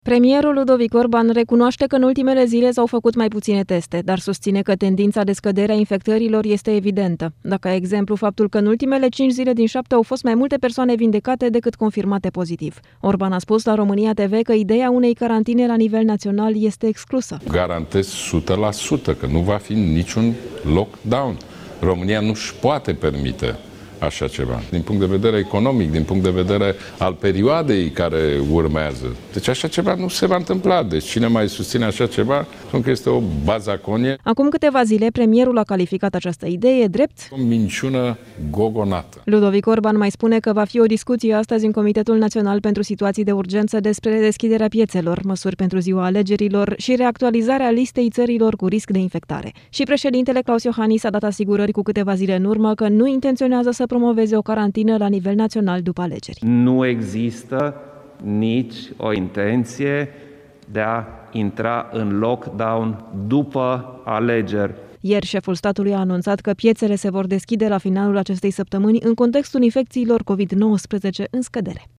Orban a spus la România TV că ideea unei carantine la nivel naţional este exclusă: